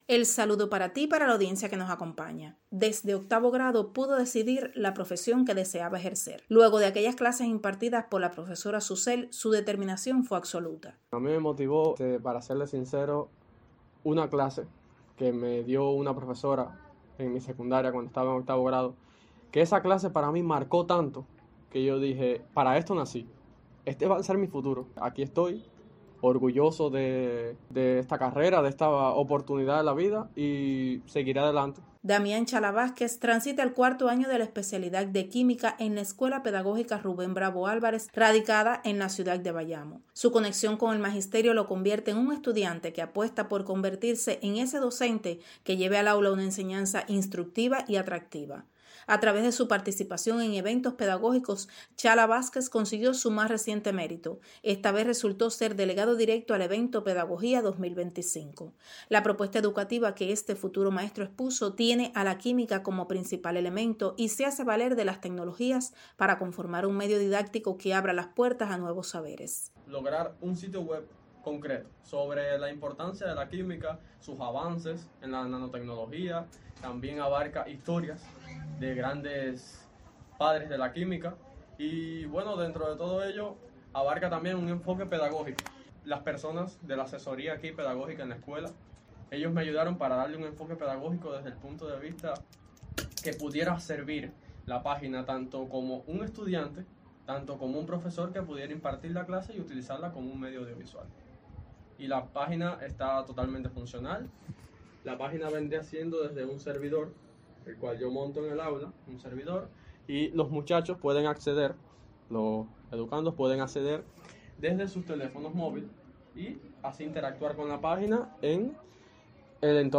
Entrevista audio